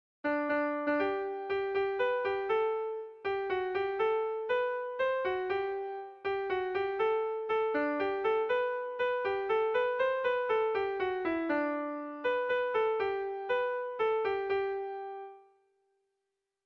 Irrizkoa
A-B-C-D